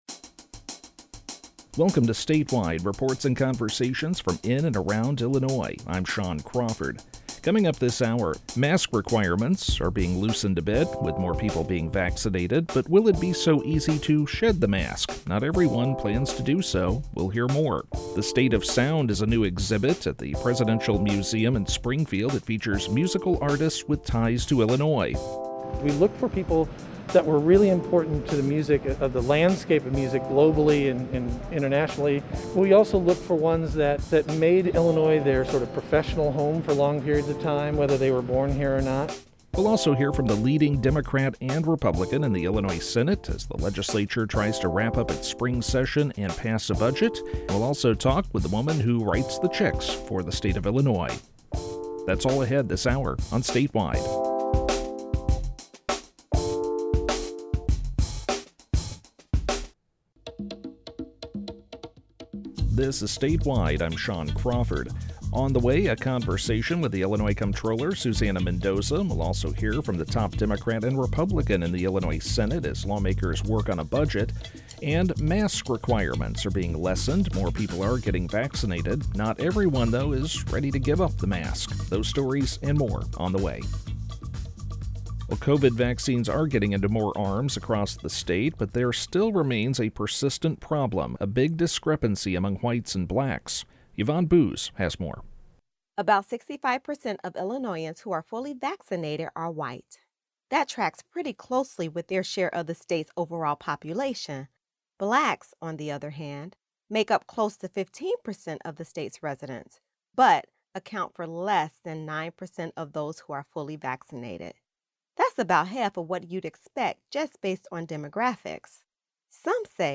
We'll listen to the top Democrat and Republican in the Illinois Senate, as well as the state's comptroller, as lawmakers work on a new budget.